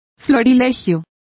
Complete with pronunciation of the translation of anthology.